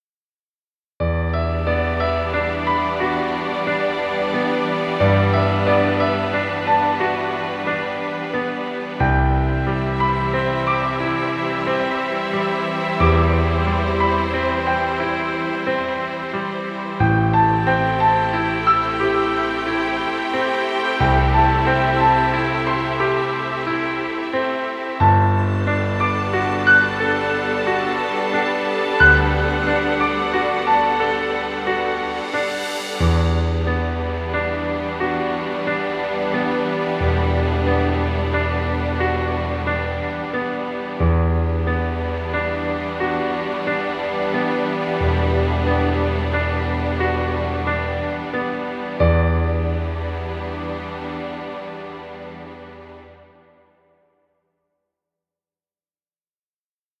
Romantic calm music.